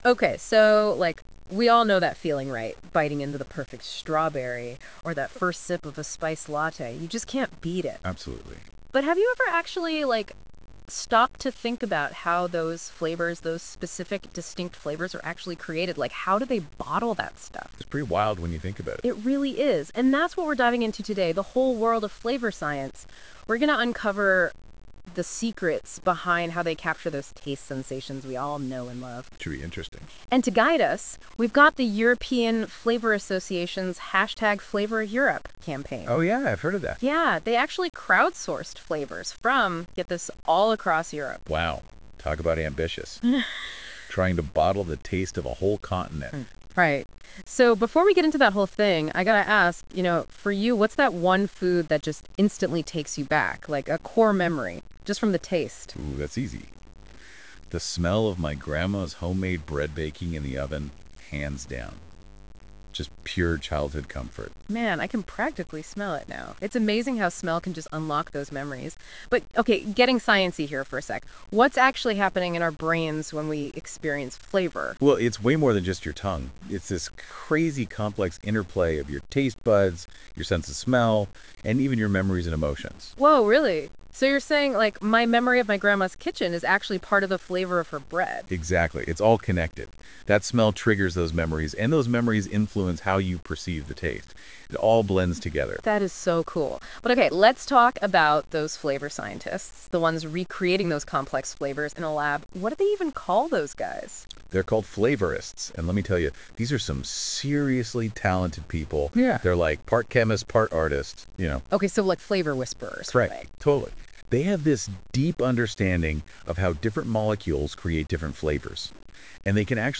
To complete the experience, you can watch the video below, listen to our AI Podcast or enjoy the music that was developed for each of these flavourings:
Flavour-of-Europe-brochure-podcast-1.wav